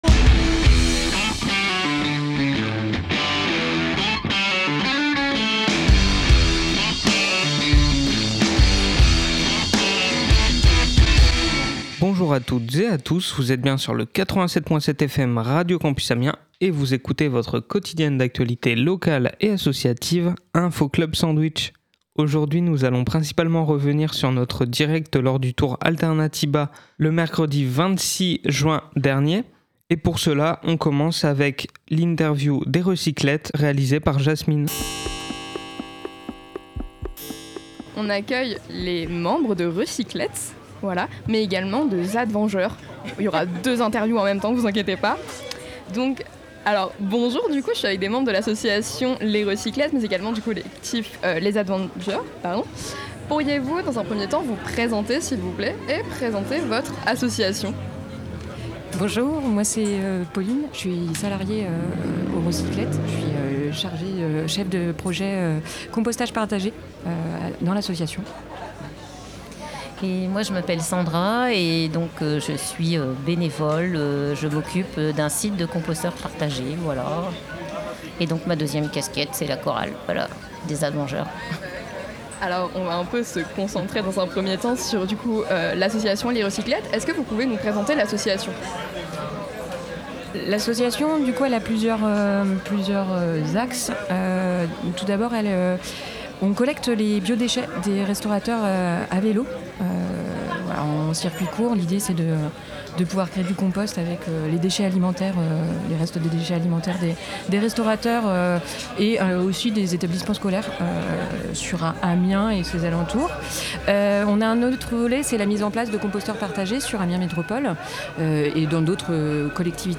Aujourd’hui, on revient sur notre direct lors du Tour Alternatiba !